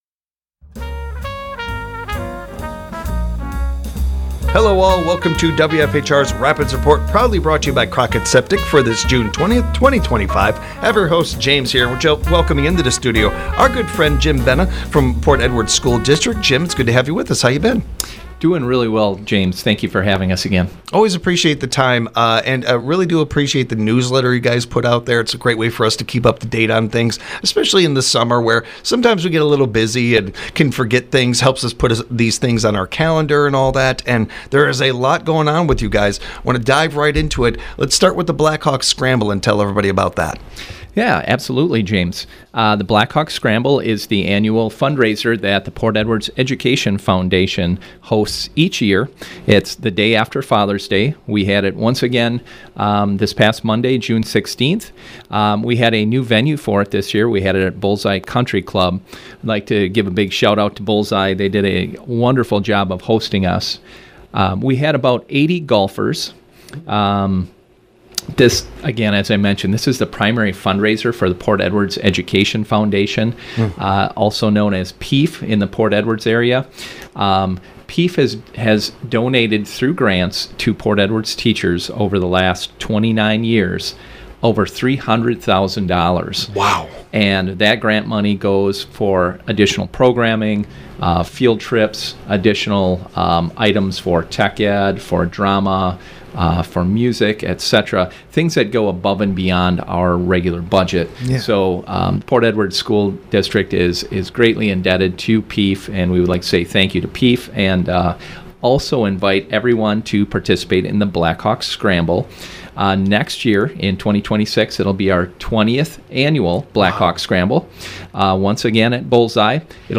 rapids report Port Edwards School District June ‘25 Guests